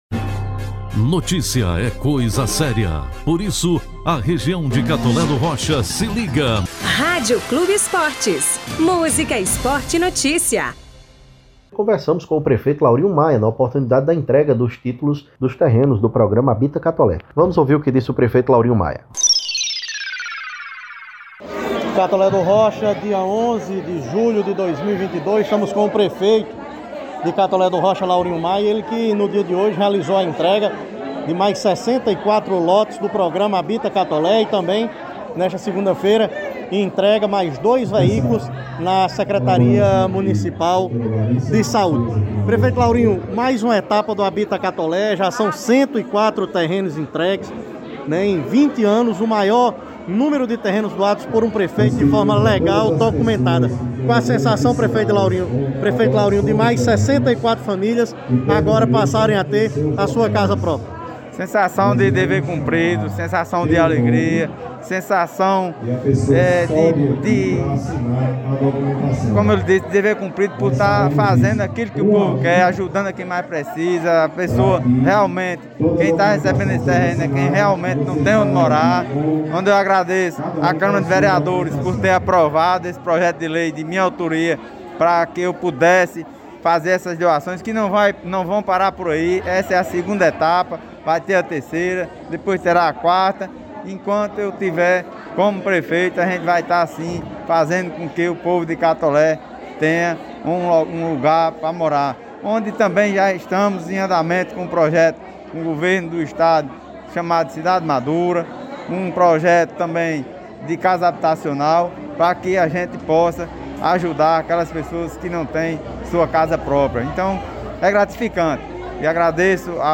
Ouça na íntegra a entrevista com o gestor municipal Catoleense: